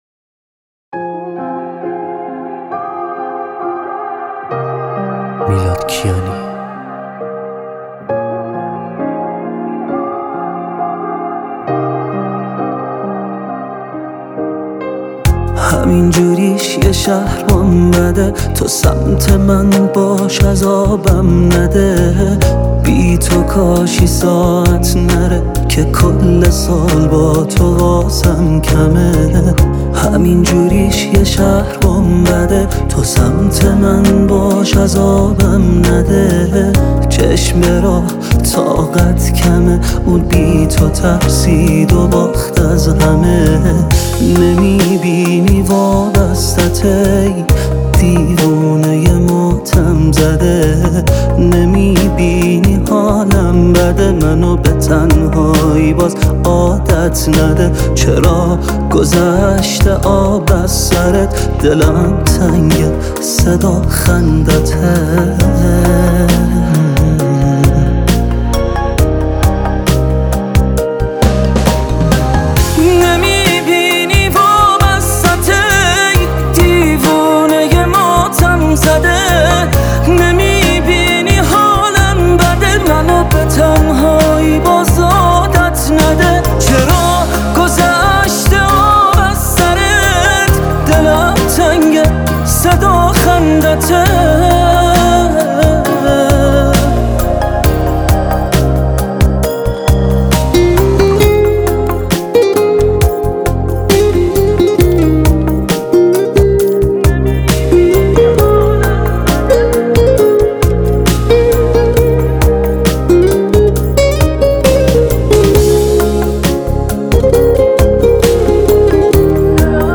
پاپ
آهنگ رپ